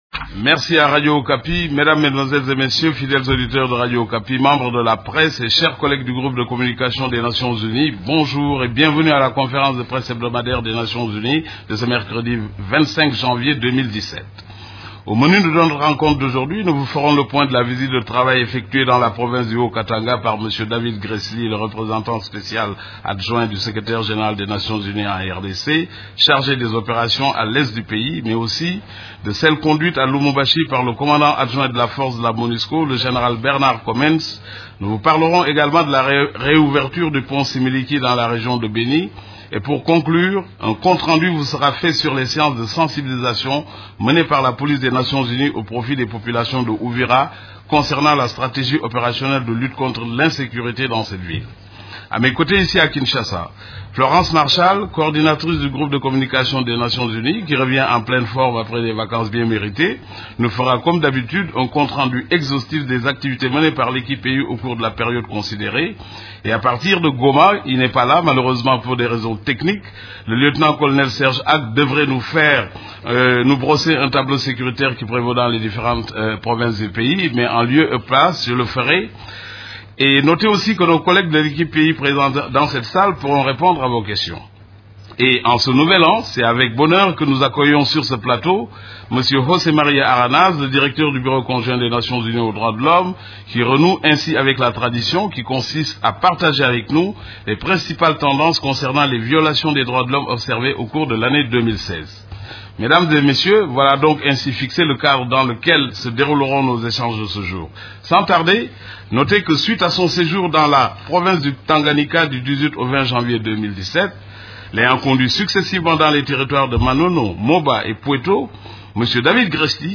Conférence de presse du 25 janvier 2017
La conférence de presse hebdomadaire des Nations unies du mercredi 25 janvier à Kinshasa a porté sur la situation sur les activités des composantes de la MONUSCO, des activités de l’Equipe-pays ainsi que de la situation militaire à travers la RDC.